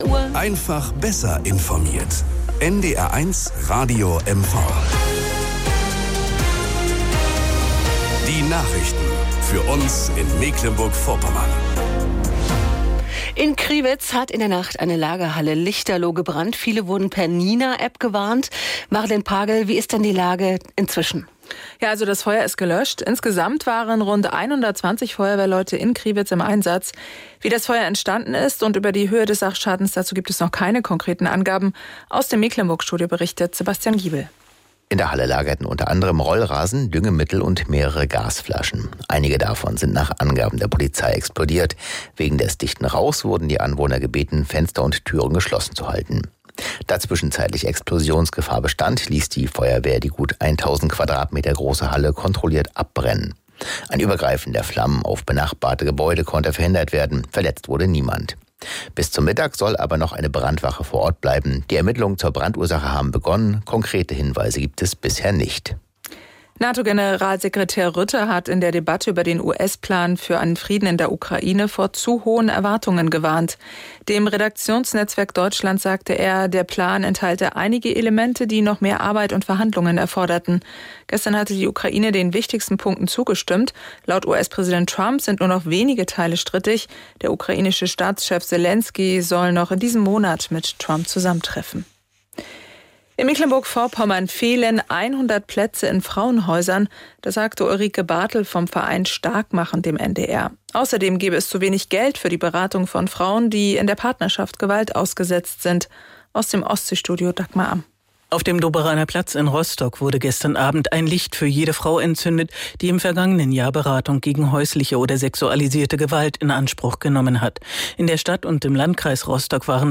Genres: Daily News, News